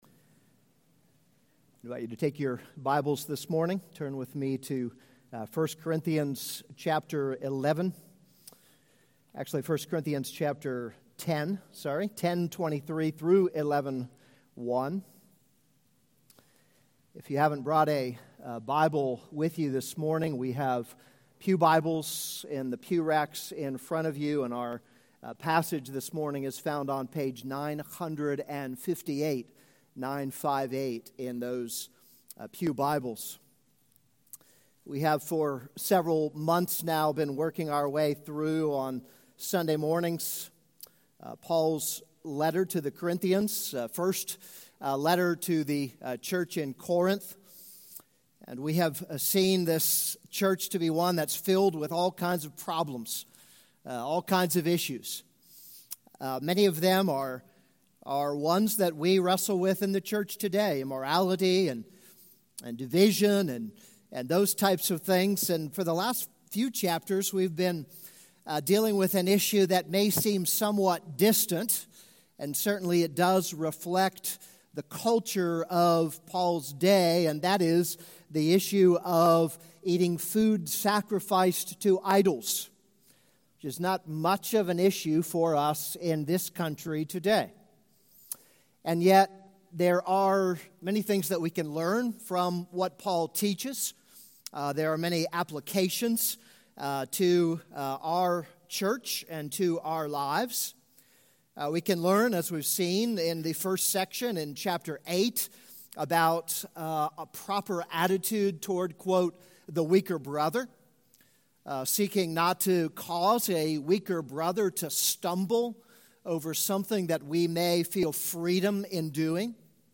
This is a sermon on 1 Corinthians 10:23-11:1.